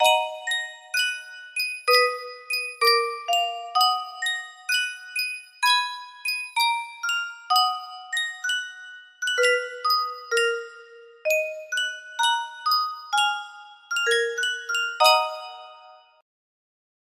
Yunsheng Music Box - Vivaldi Laudate pueri Dominum 5942 music box melody
Full range 60